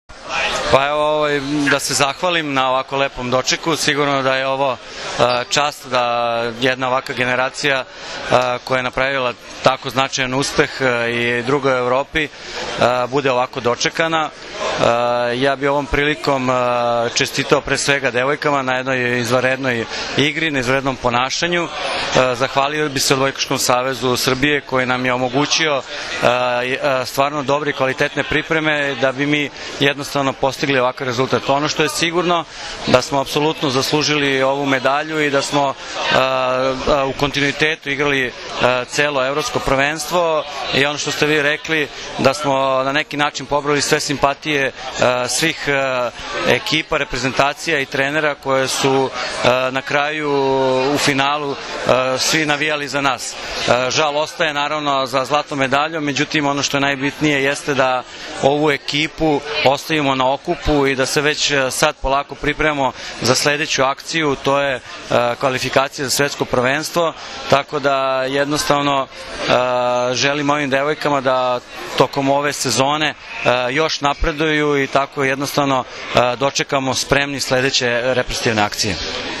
Na beogradskom aerodromu “Nikola Tesla”, srebrne juniorke su sa cvećem dočekali predstavnici Odbojkaškog saveza Srbije.
IZJAVA